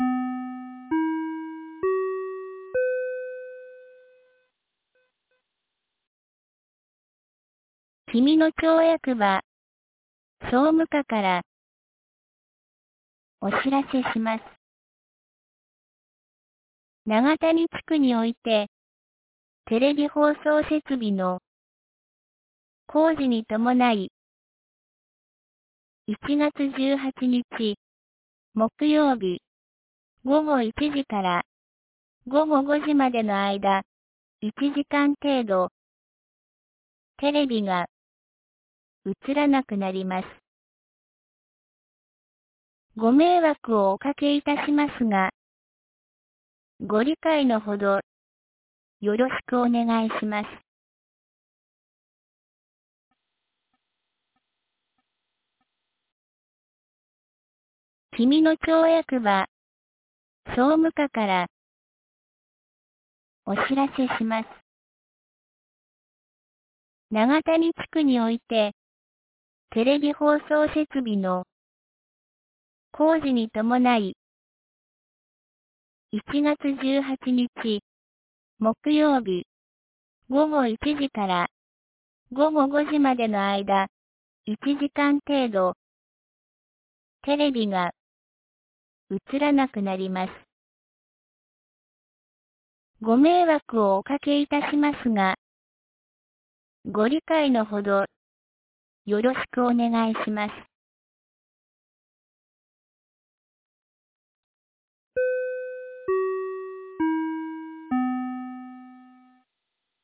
2024年01月16日 12時31分に、紀美野町より東野上地区へ放送がありました。
放送音声